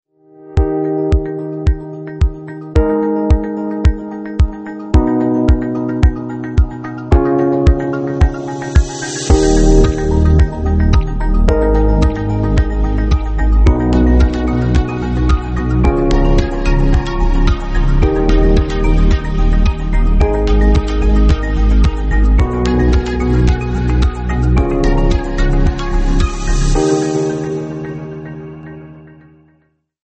موزیک مخصوص لوگو موشن و آرم استیشن
/ / منتشر شده در لوگو موشن, ملایم و آرامش بخش